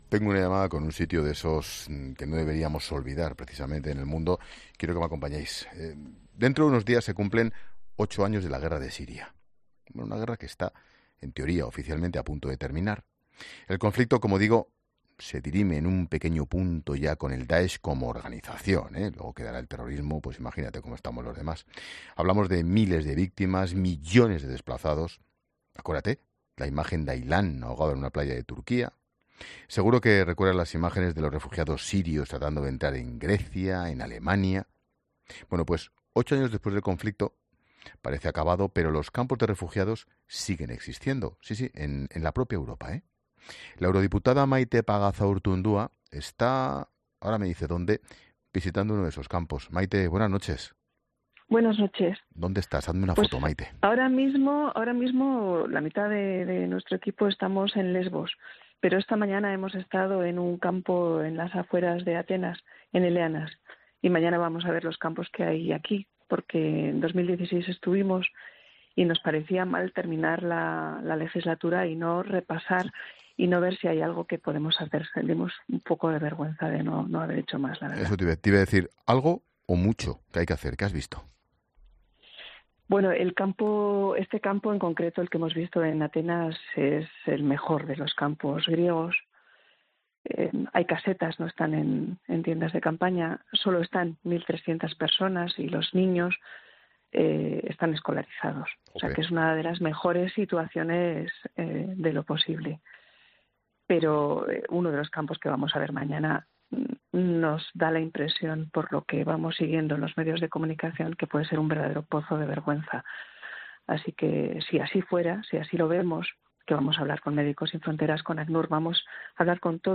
Hablamos en COPE con la eurodiputada en el último tramo de legislatura en plena visita a los campos de refugiados para sirios en Grecia